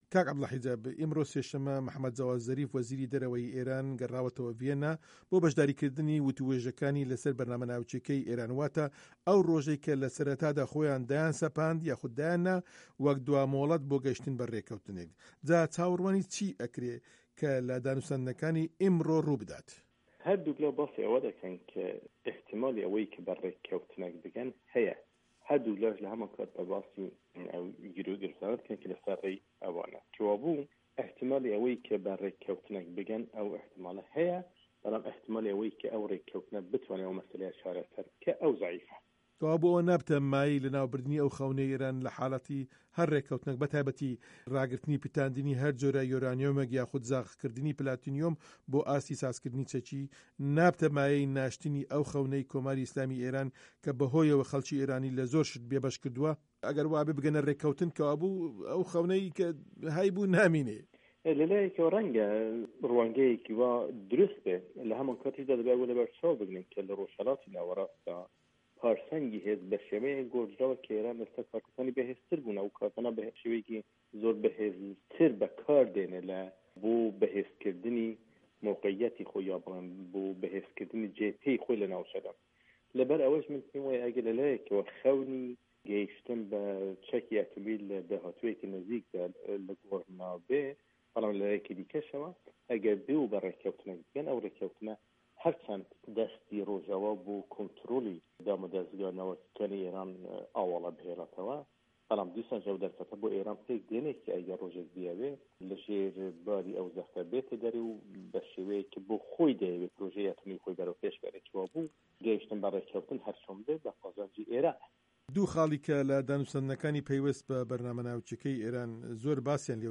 وەتووێژی